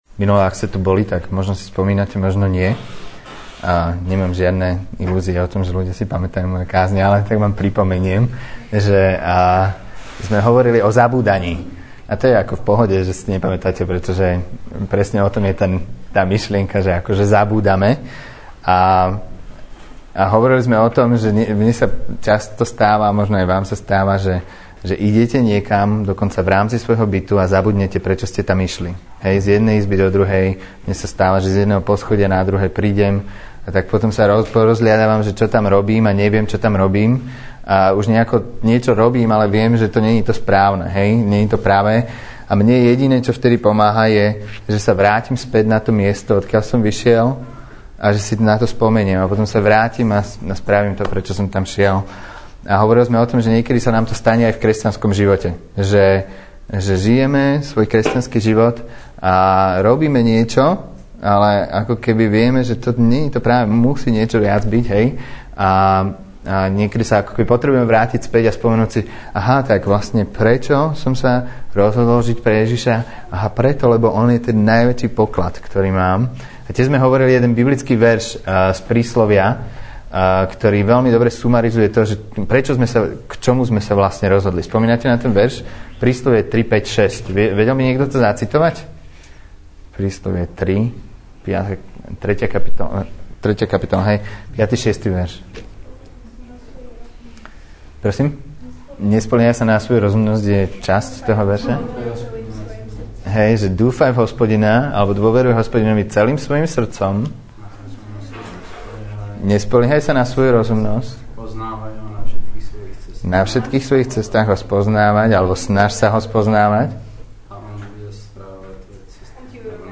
Nahrávka kázne Kresťanského centra Nový začiatok z 22. marca 2009
Vypočujte si druhú kázeň zo série Spomínajme.